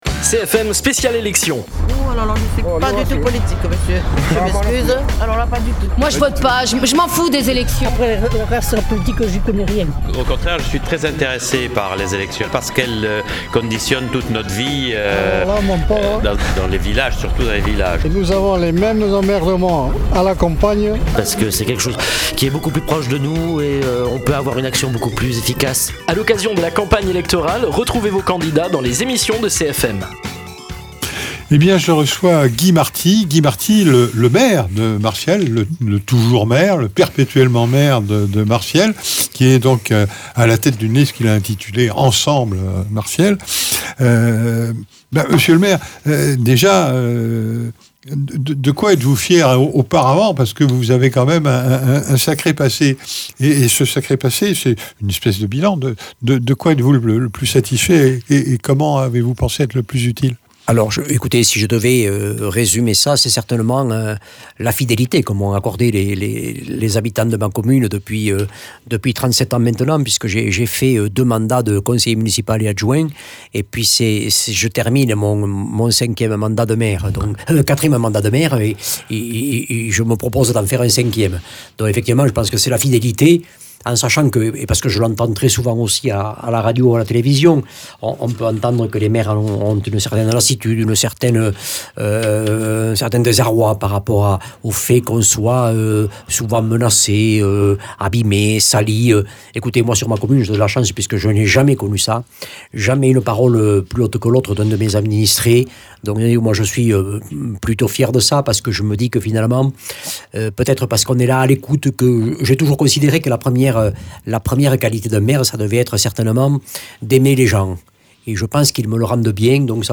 Invité(s) : Guy Marty, pour la liste Martiel Ensemble.